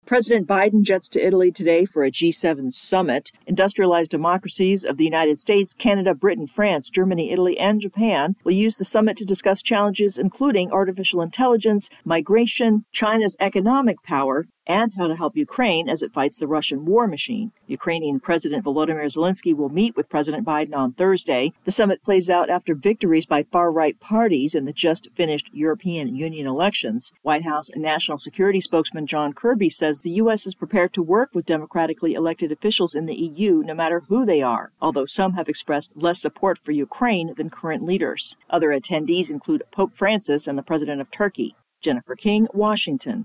Heading to a summit in Italy, President Biden aims to bolster support for Ukraine. AP correspondent
reports